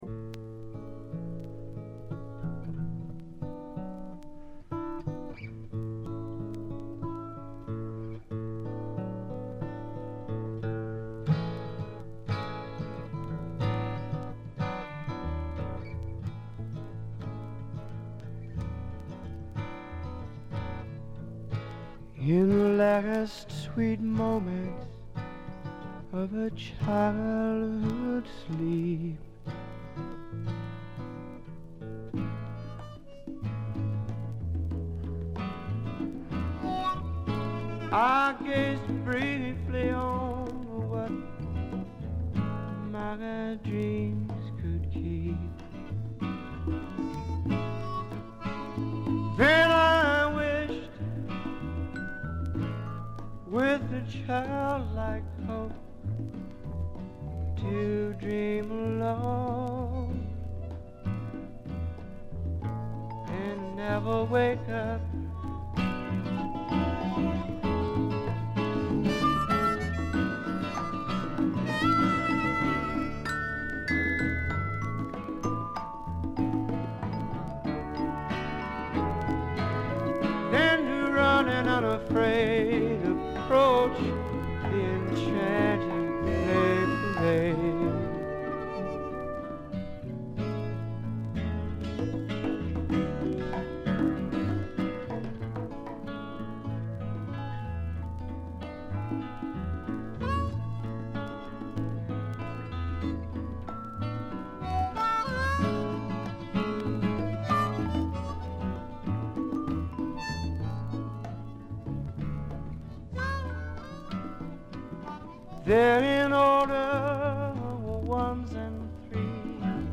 バックグラウンドノイズ、チリプチ多め大きめですが凶悪なものや周回ノイズはありません。
試聴曲は現品からの取り込み音源です。
Guitar
Drums